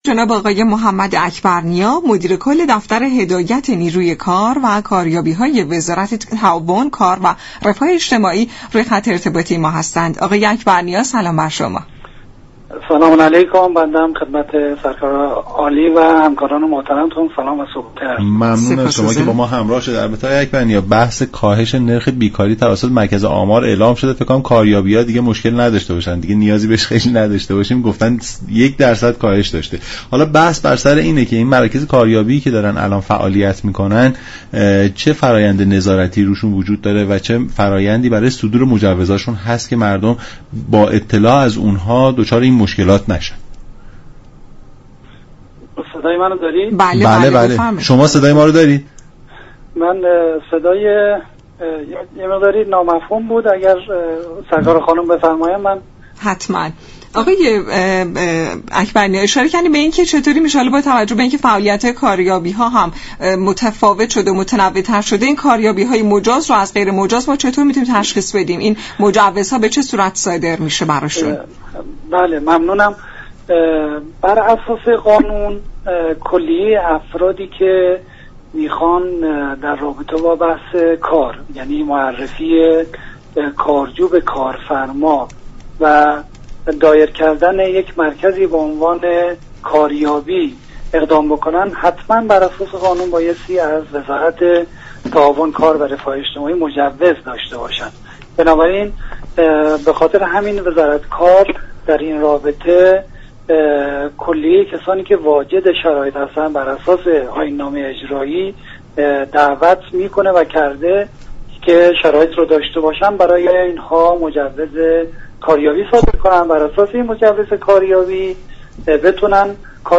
محمد اكبرنیا در گفت و گو با برنامه «نمودار» از نحوه نظارت بر مراكز كاریابی گفت : بر اساس قانون تمام افرادی كه خواهان دایر كردن مراكز كاریابی هستند، حتما باید مجوزهای لازم را از وزارت كار دریافت كنند.